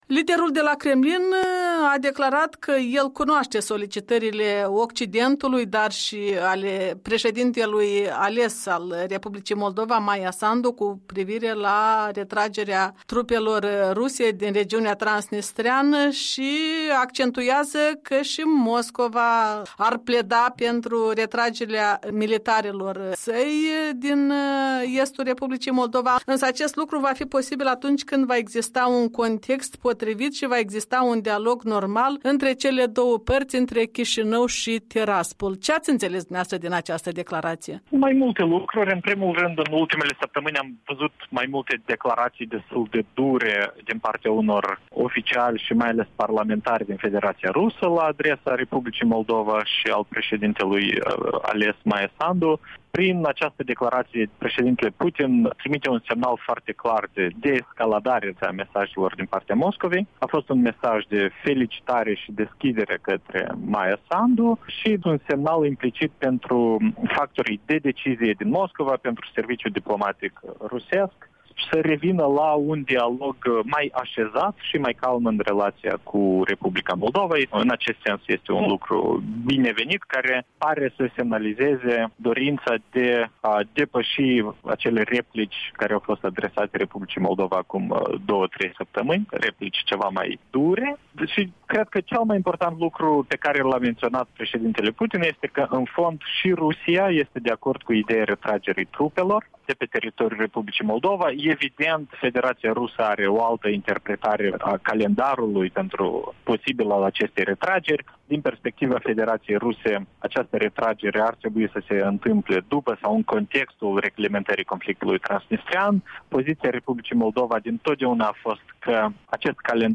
Interviu cu NIcu Popescu, fost ministru de externe